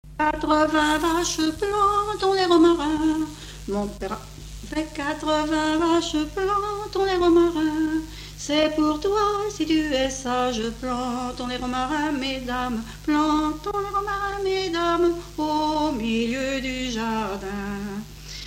Localisation Luçon
Genre laisse
Catégorie Pièce musicale inédite